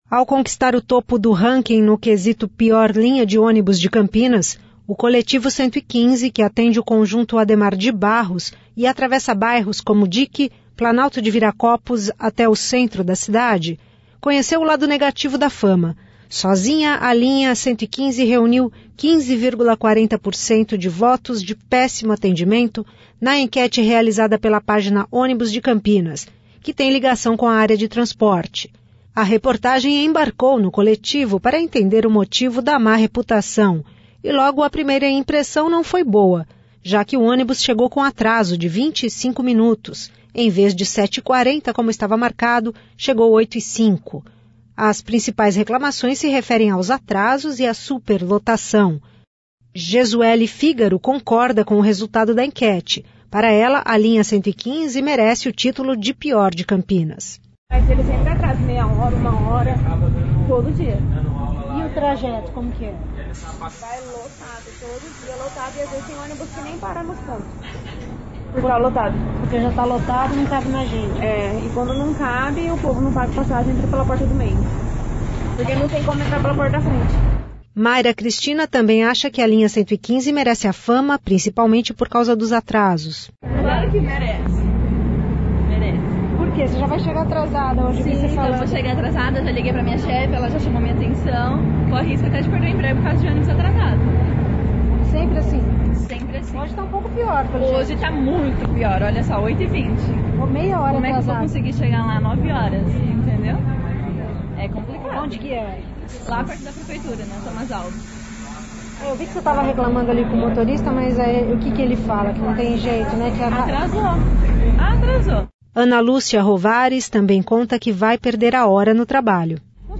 Reportagem embarca no coletivo eleito como a pior linha de Campinas
A reportagem embarcou no coletivo para entender o motivo da má reputação e logo a primeira impressão não foi boa, já que o ônibus chegou com atraso de vinte e cinco minutos.